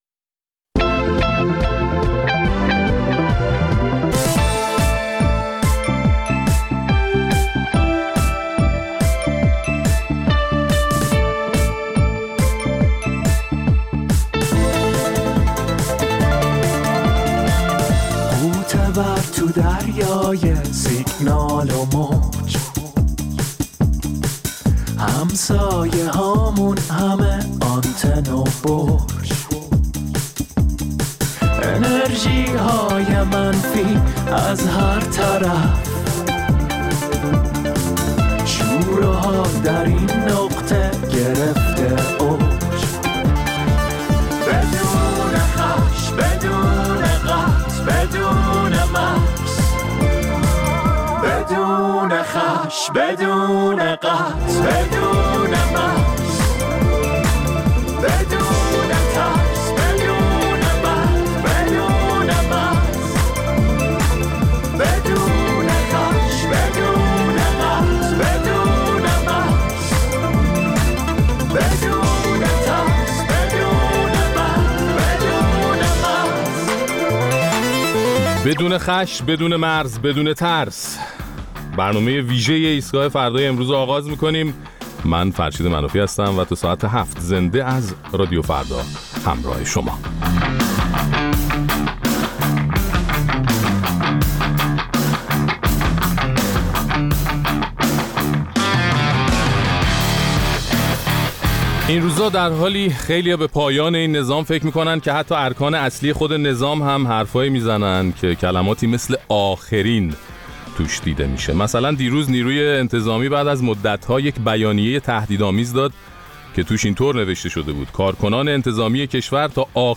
در این ایستگاه فردای ویژه، با توجه به ادامه اعتراضات سراسری در ایران، به حواشی و متن این اعتراضات می‌پردازیم و نظرات و مشاهدات شنوندگان برنامه را هم در این باره می‌شنویم.